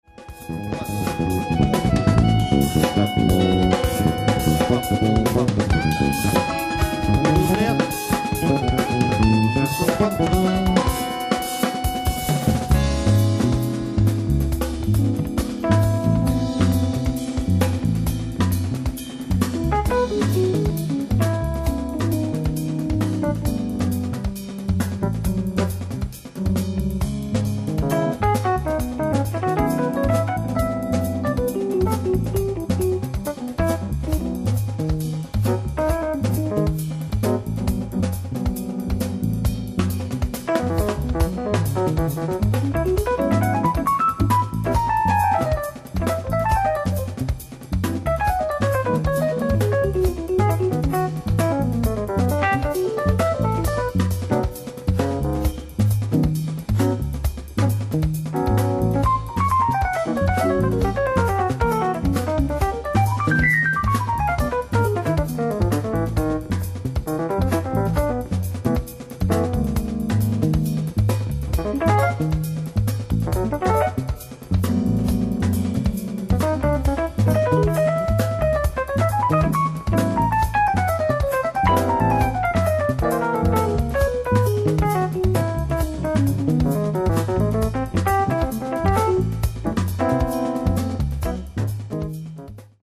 Disc 1 & 2：ライブ・アット・パリ、フランス 04/09/2000
※試聴用に実際より音質を落としています。